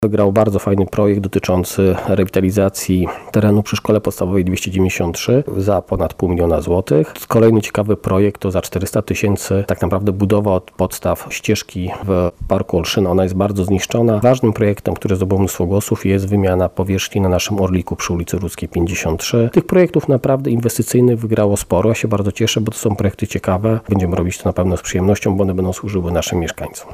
– W dzielnicy wygrało wiele istotnych projektów – dodaje burmistrz dzielnicy Grzegorz Pietruczuk.